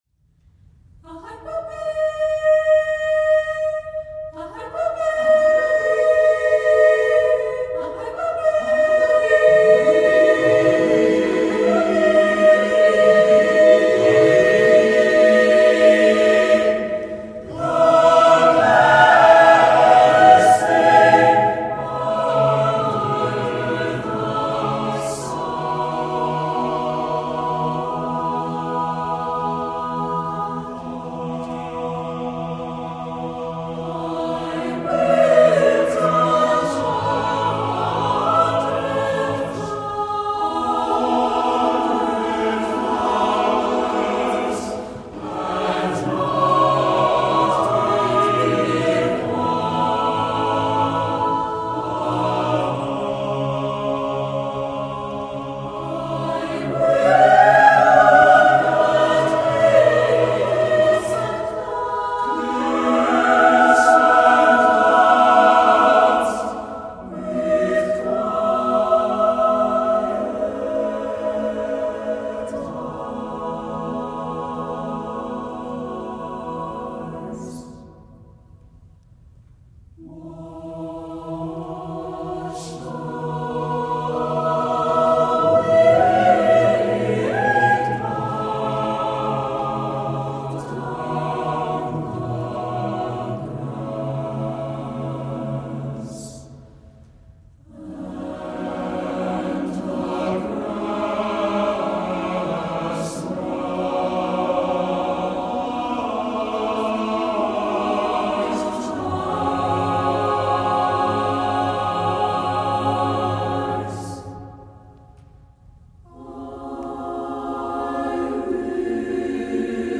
SATB a cappella chorus with divisi